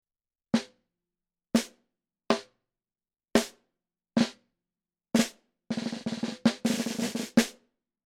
Microphone Shootout – Snare Drum Edition
In order to eliminate as many variables as we could, the microphones were set up in as close to the same spot as we could get and I played every test the same way: Center hit, off-center hit, flam, nine-stroke roll.
We had two of almost all of these microphones, so the snare drum is double-miked for all but a few tests (which I’ll disclose when we get to them) – one microphone on the top head, the other on the bottom placed right around the snares themselves.
The first microphone listed is panned hard left and the second is hard right.
Next is the oddball matchup – Samson Q3 (discontinued) vs. Groove Tubes Convertible.
snare-mic-shootout-4.mp3